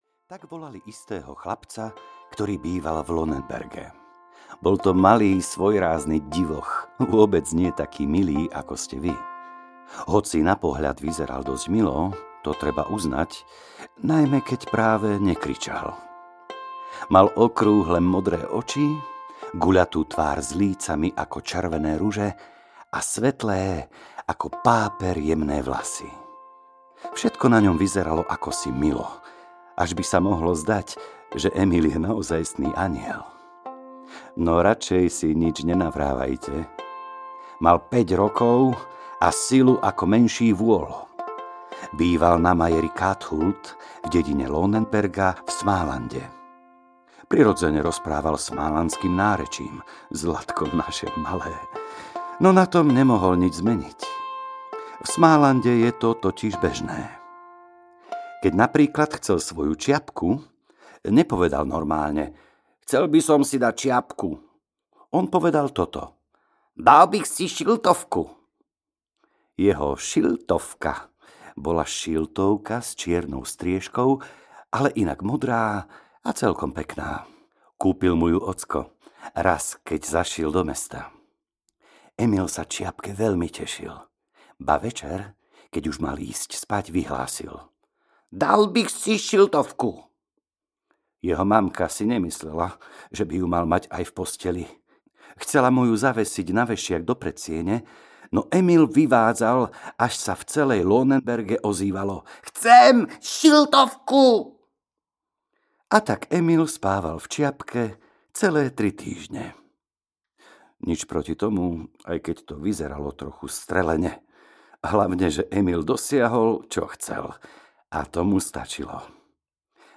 Emil z Lönnebergy audiokniha
Ukázka z knihy